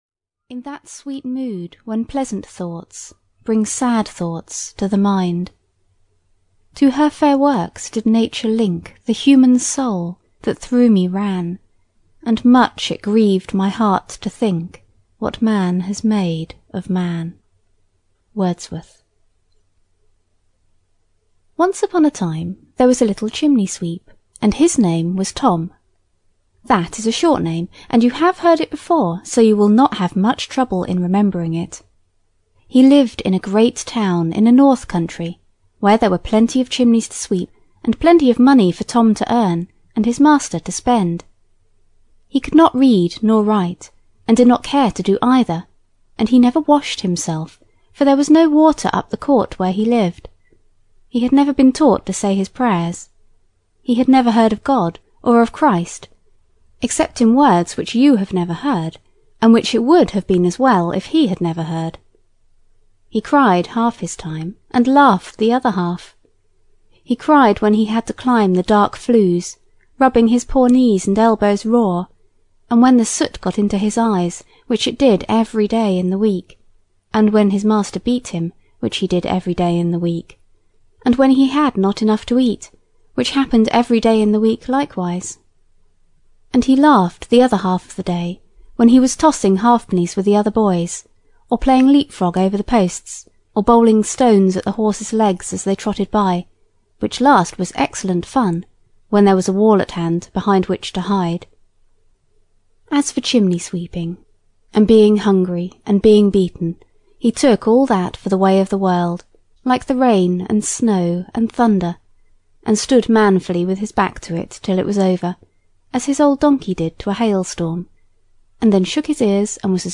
The Water-Babies (EN) audiokniha
Ukázka z knihy